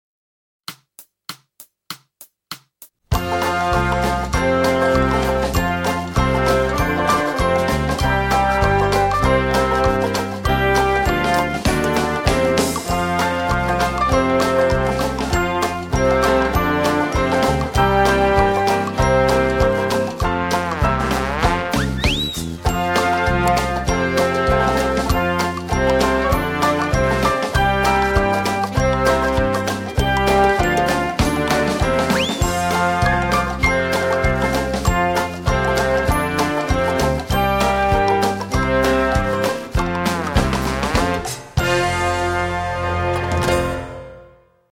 Concerto